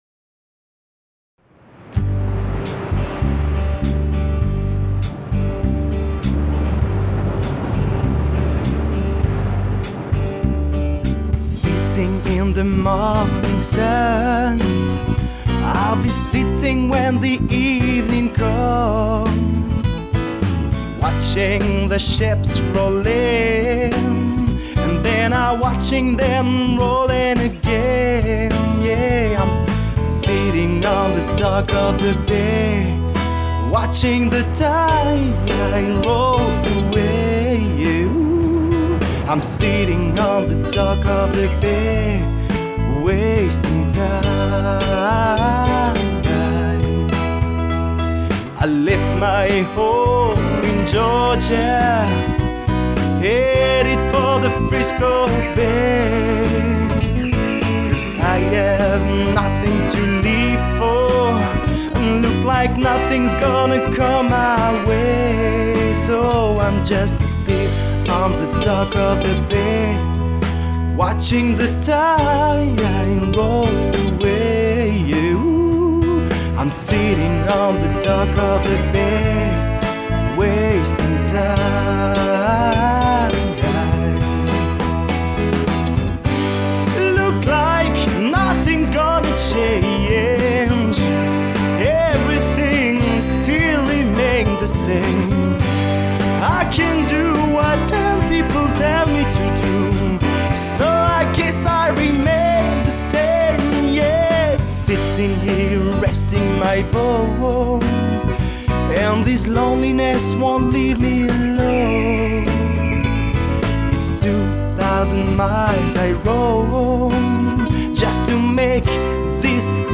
Et il chante bien !!